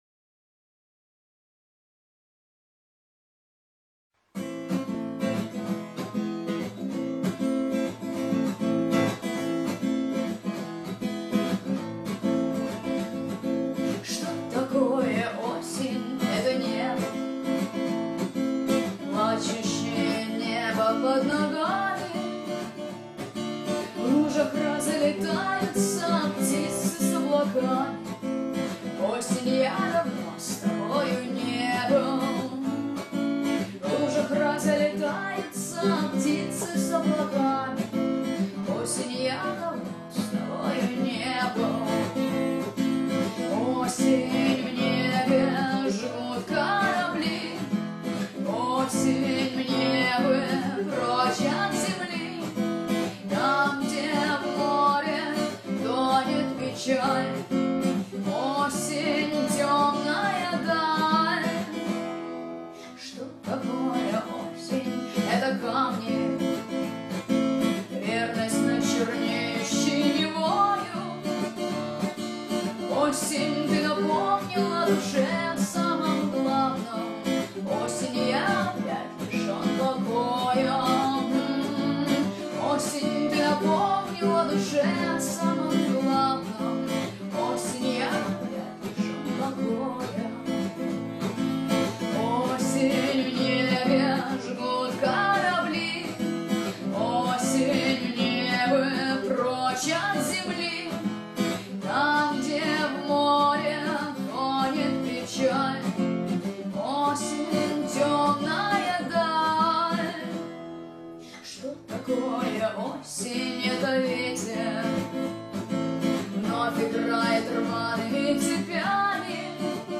По-моему, я несколько аккордов неправильно сыграла.